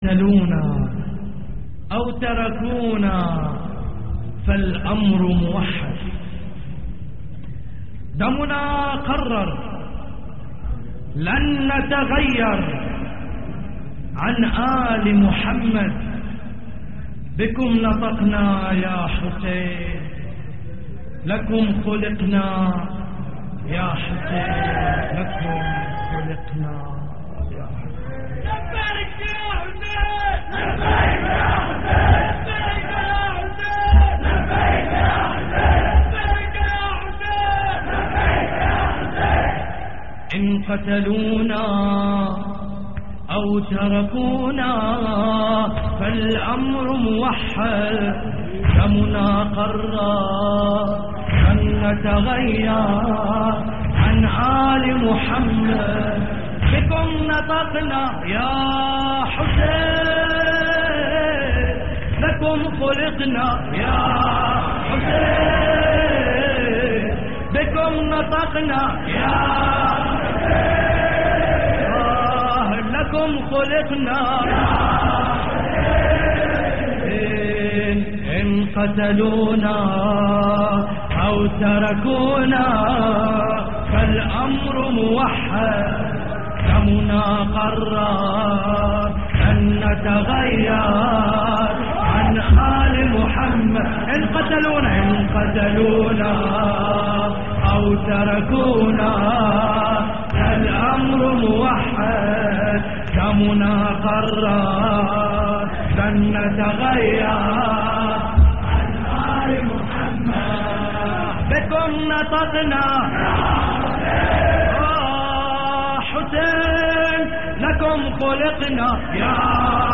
اللطميات الحسينية